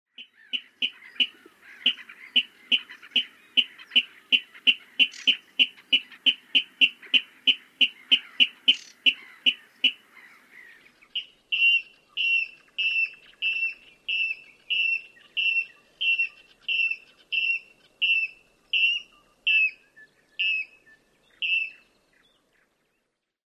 Rytmit ovat helposti tunnistettavia ja yksinkertaisia.
Luhtakana vetelee gyk-gyk-gyk-pohjaisia, rytmiltään vaihtelevia sarjoja.